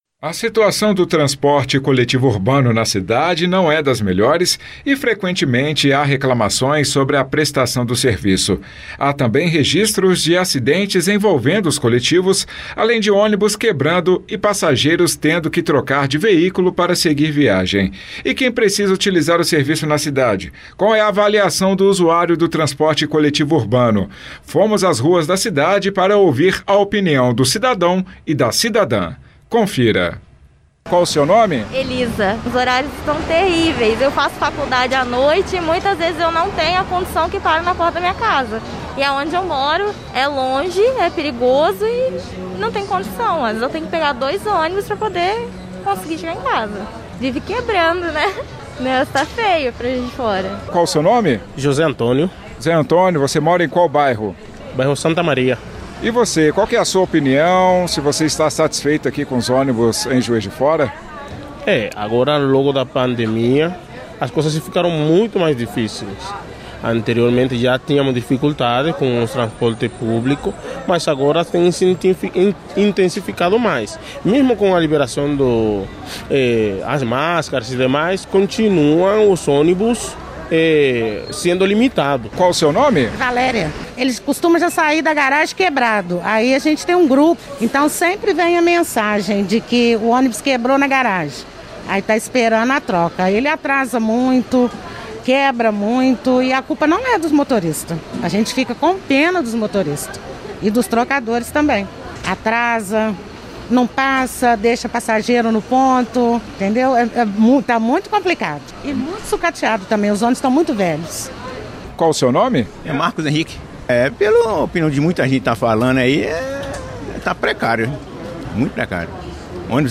Povo-fala-onibus.mp3